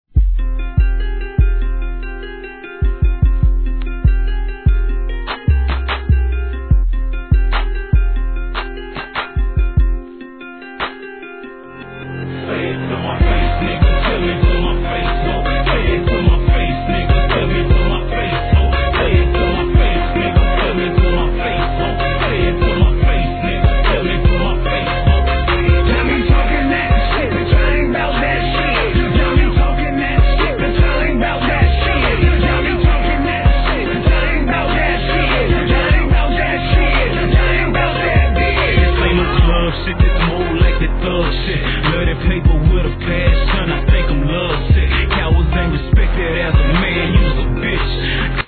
HIP HOP/R&B
怒派手なシンセ使いでSOUTHファンを直撃!